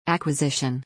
[æk.wəˈzɪʃ。ə N]